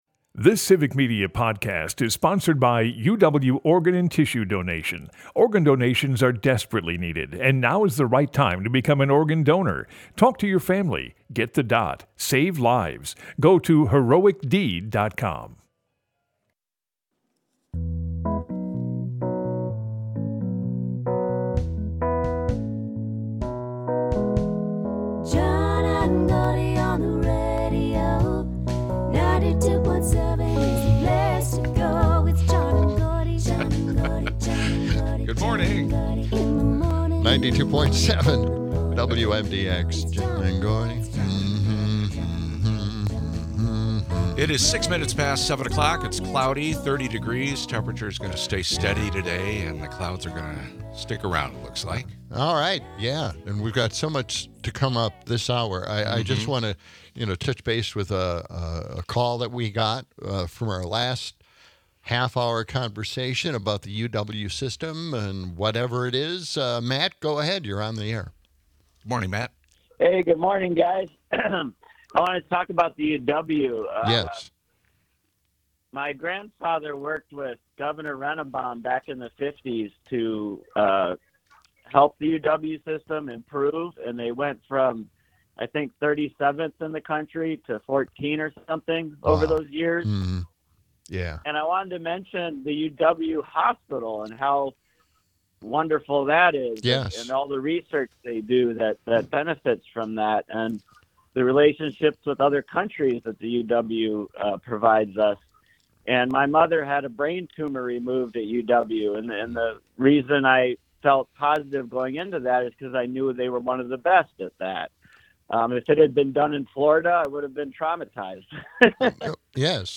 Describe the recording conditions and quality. Wrapping up the show, we hear an abridged version of SNL's weekend update, and the guys talk healthcare and billionaires.